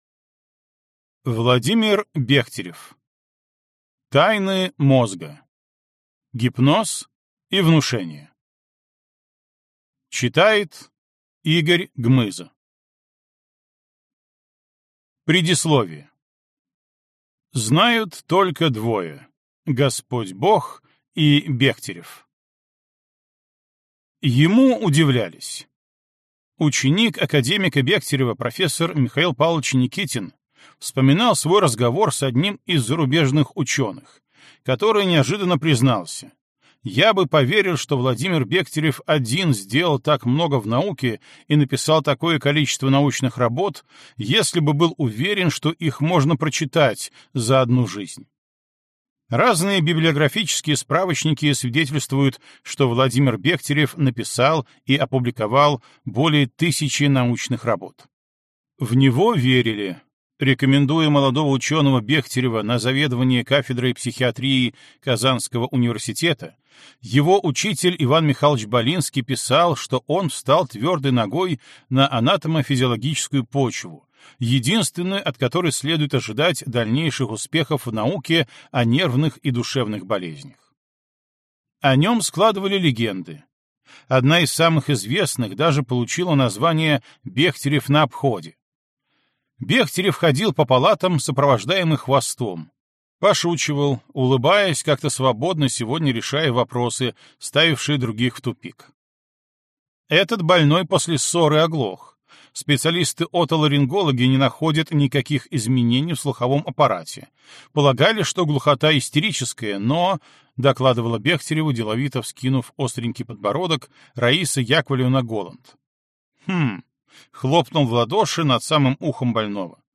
Аудиокнига Тайны мозга: гипноз и внушение | Библиотека аудиокниг